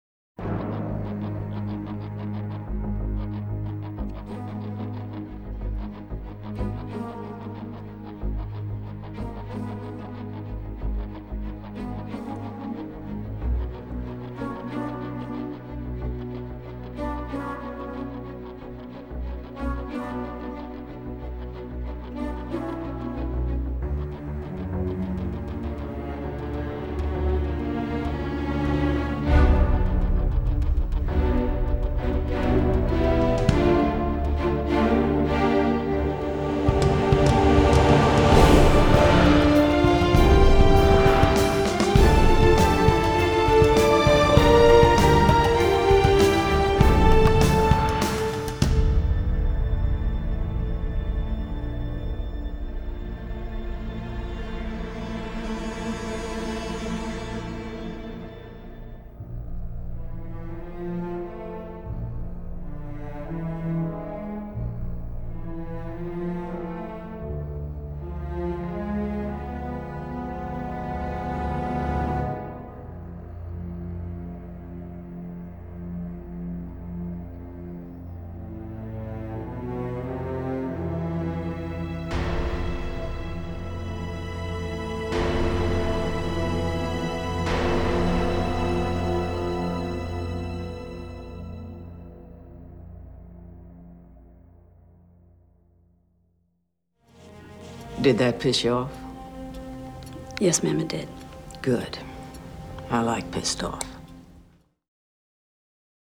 Тип:Score